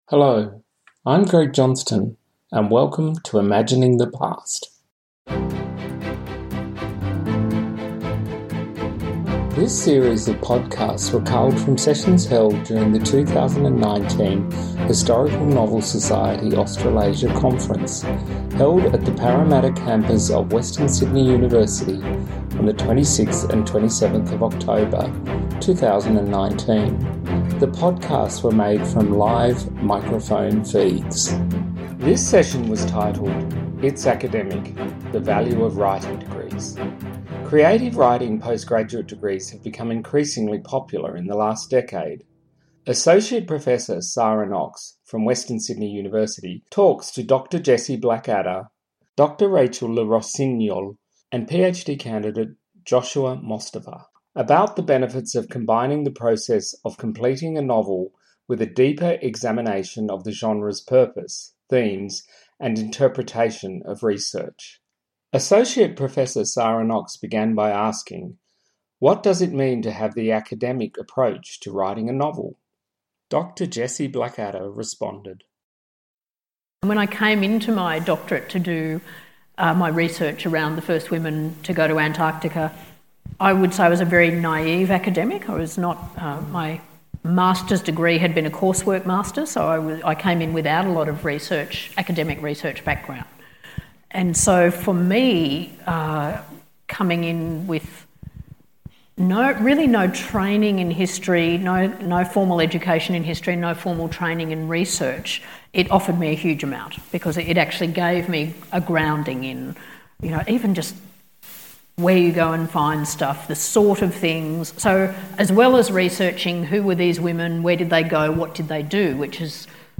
Imagining the Past’s new season features live recorded sessions from the 2019 HNSA conference